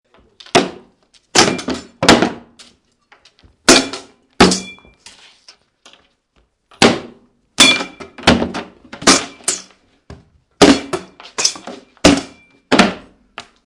Download Fighting sound effect for free.
Fighting